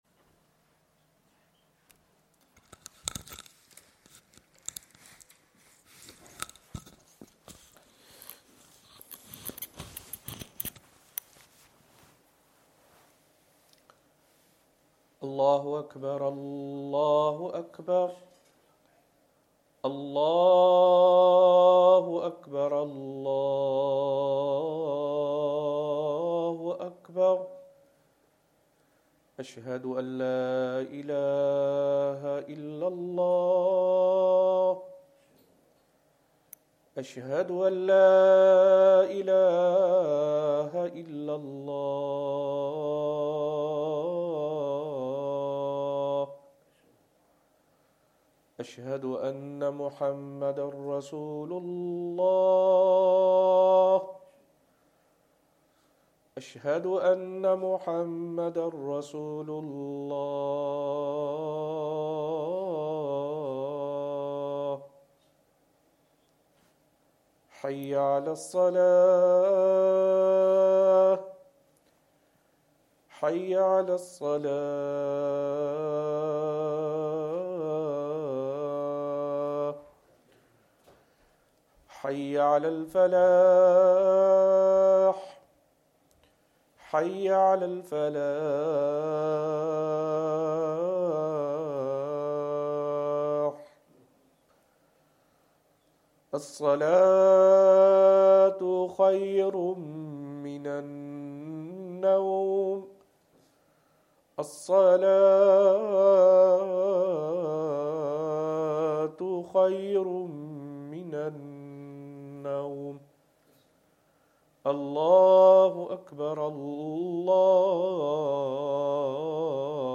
Azan
Madni Masjid, Langside Road, Glasgow